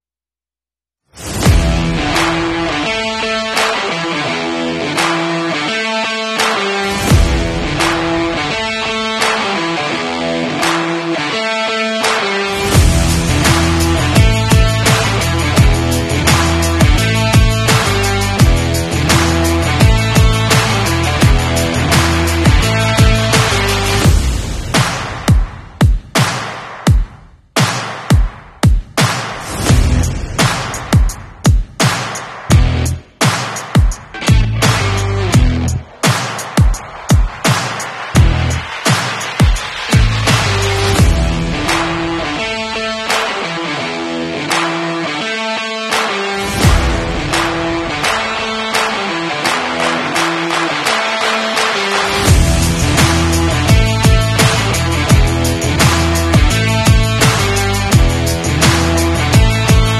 Subwoofer 12" Autolab SL12. 2. sound effects free download